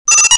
ShortWave.wav